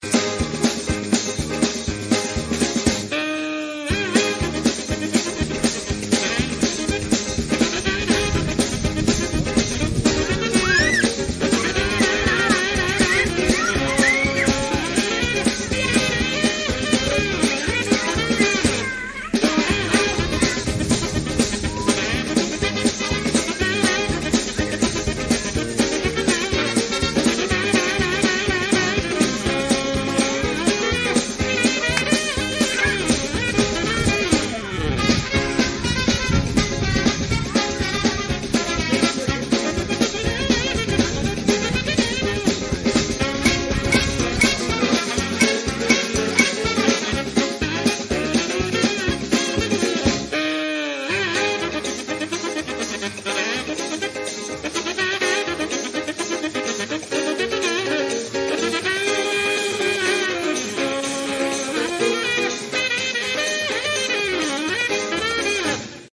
Kennt jemand dieses lustige Saxophon-Liedchen?
Wie heißt dieses Saxofonstück?
Text gibts auch Keinen im Song.